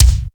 BIG BD 5.wav